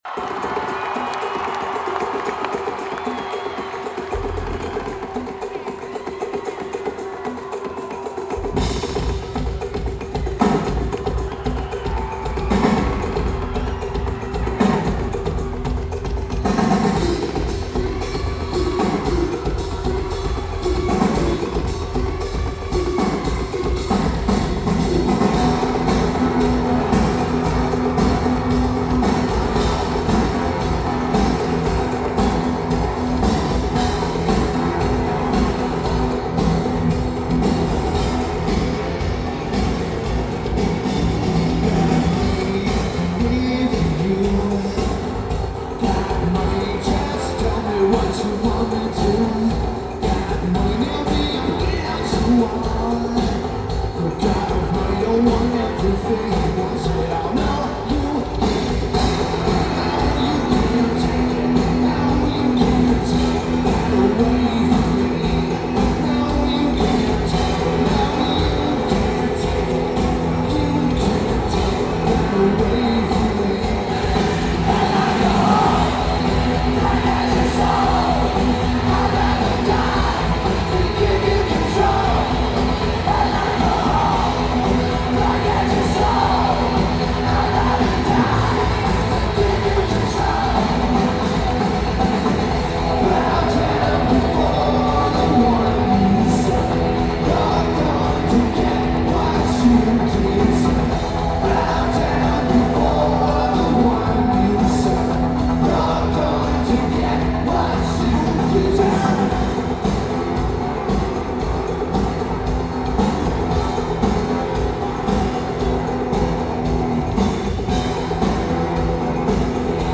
Providence Civic Center
Lineage: Audio - AUD (CSBs + Sony TCD-D8)
There is no EQ'ing done to this tape.